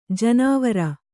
♪ janāvara